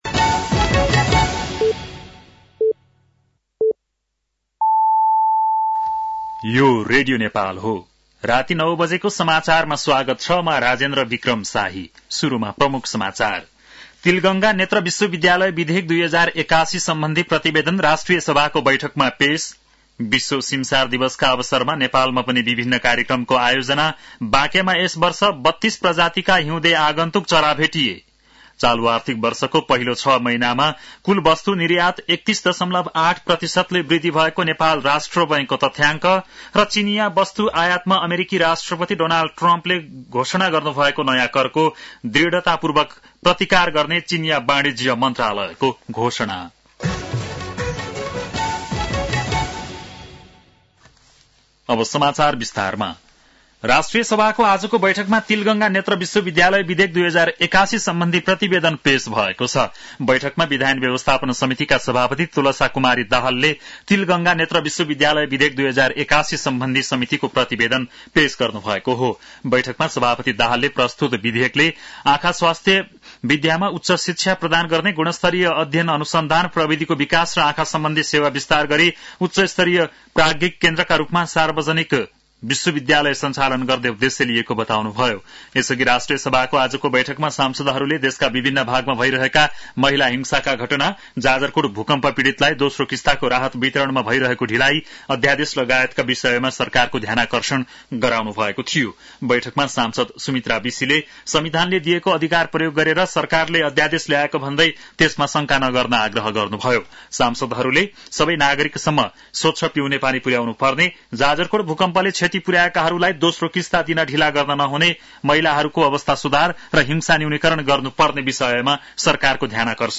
बेलुकी ९ बजेको नेपाली समाचार : २१ माघ , २०८१
9-PM-Nepali-News-10-20.mp3